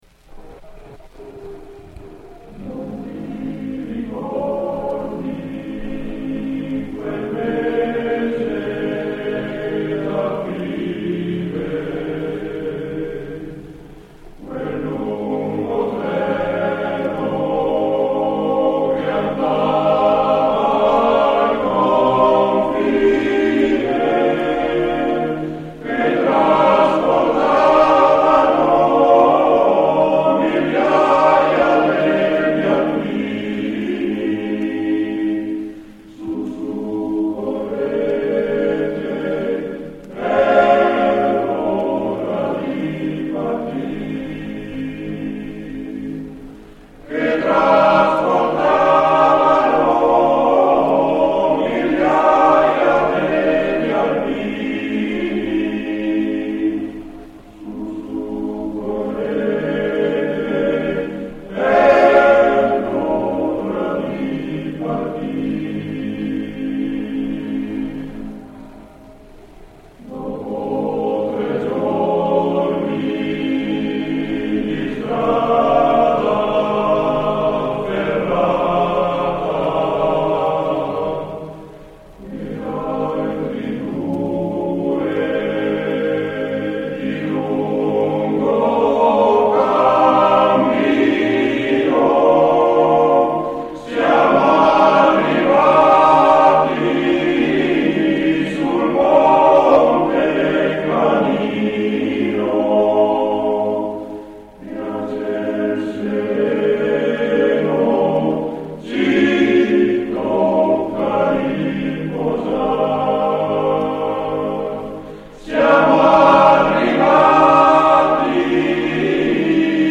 REPERTORIO DEL CORO ANA AVIANO